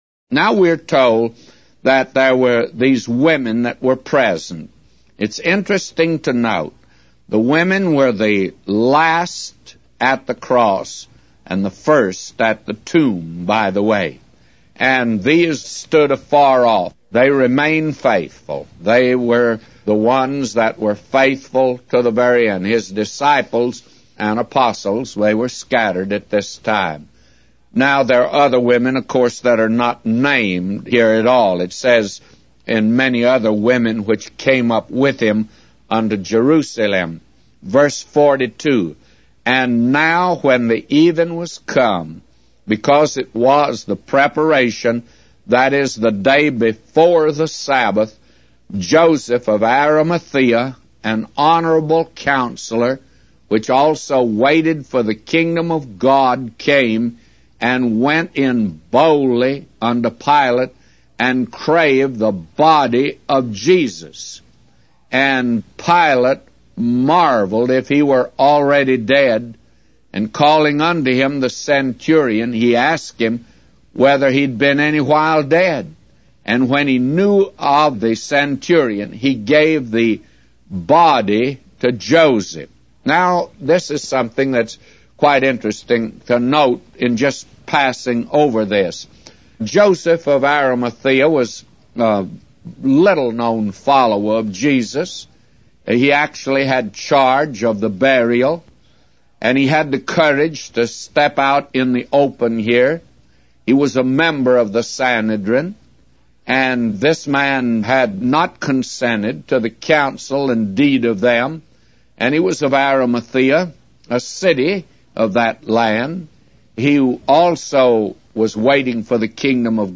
Evening Bible Reading - Mark 15